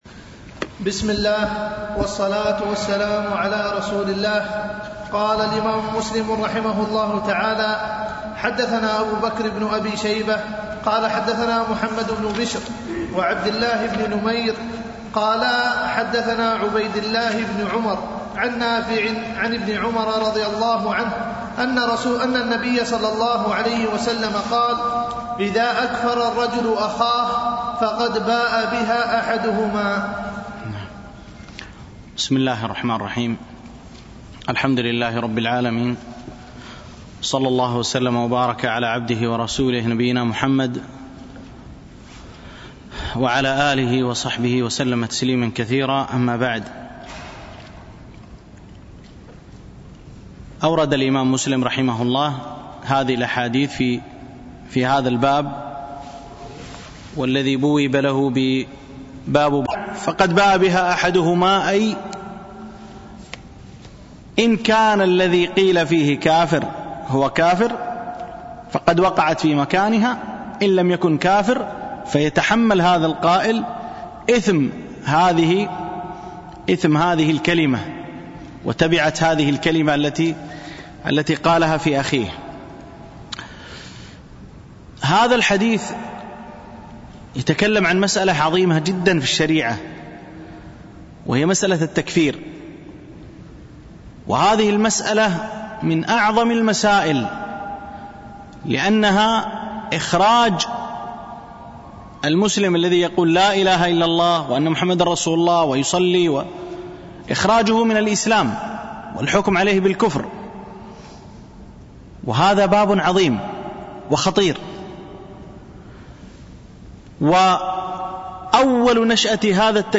الألبوم: دروس مسجد عائشة (برعاية مركز رياض الصالحين ـ بدبي)
MP3 Mono 22kHz 32Kbps (CBR)